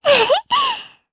Lilia-Vox_Happy2_jp.wav